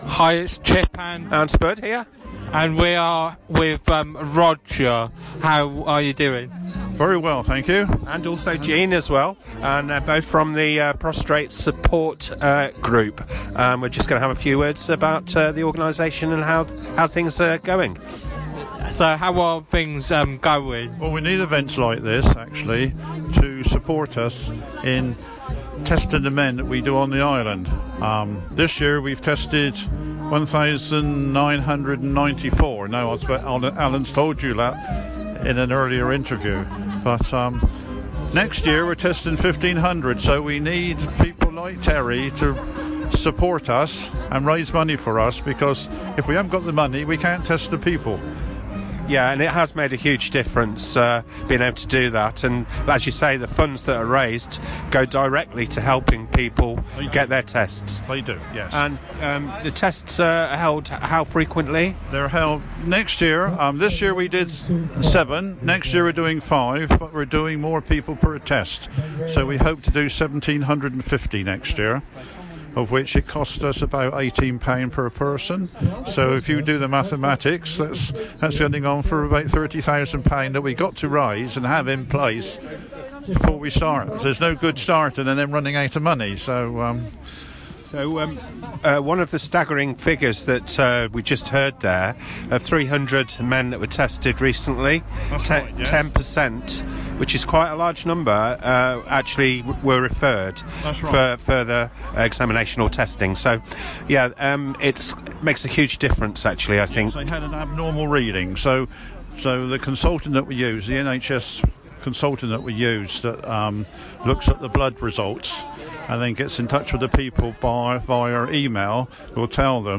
Interview (Prostrate Cancer support Group long lane Christmas Lights 2023)